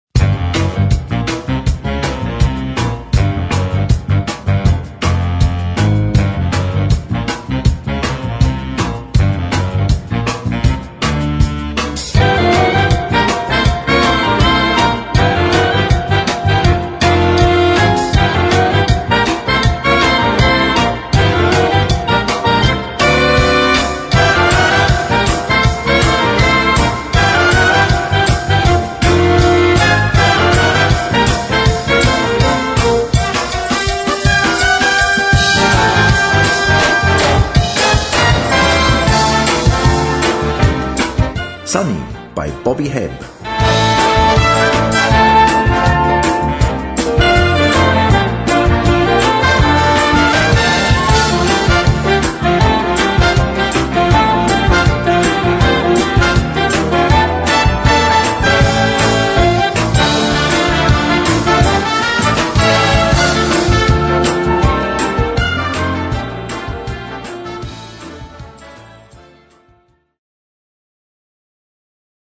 Adapt. voor brass band
Noten für Blasorchester, oder Brass Band.